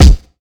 Kicks
WU_BD_251.wav